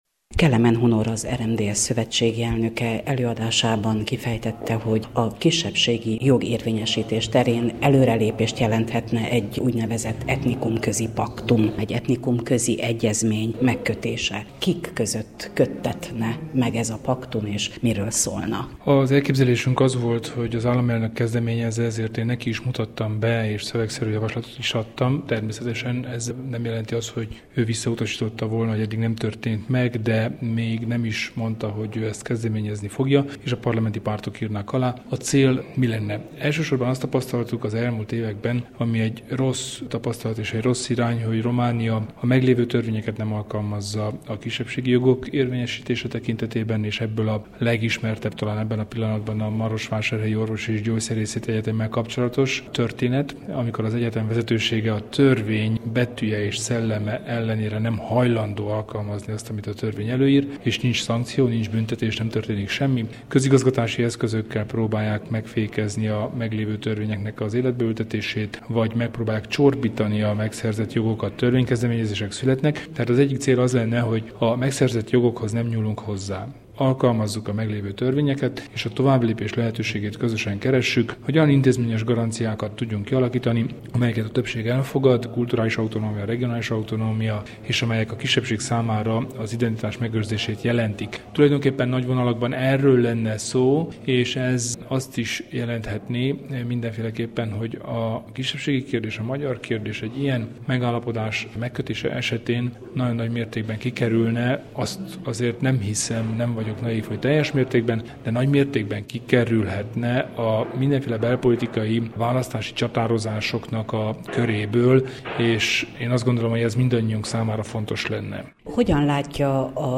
Kelemen Hunor és Frunda György nyilatkozott: